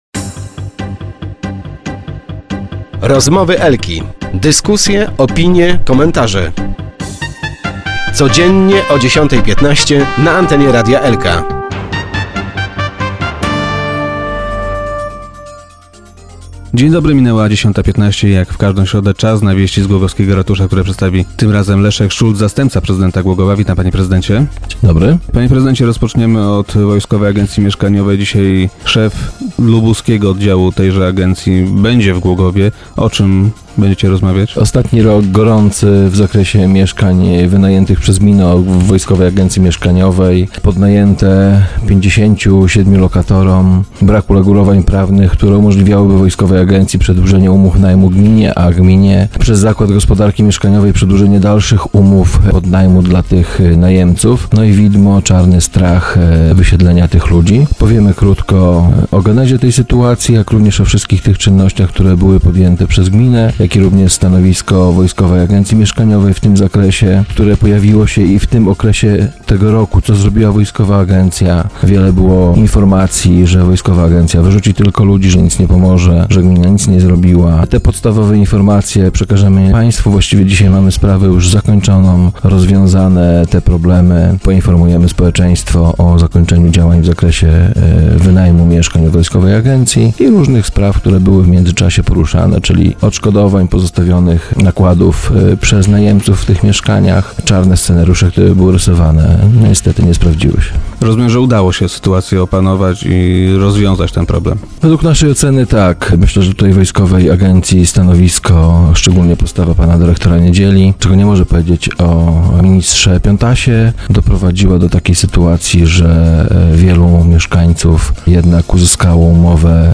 Władzom miasta nie udało się jeszcze przesiedlić wszystkich jego mieszkańców. - Są tacy, którzy nie chcą przenieść się do oferowanych przez nas lokali - powiedział Leszek Szulc, zastępca prezydenta, który był gościem dzisiejszych Rozmów Elki.